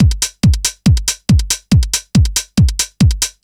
NRG 4 On The Floor 001.wav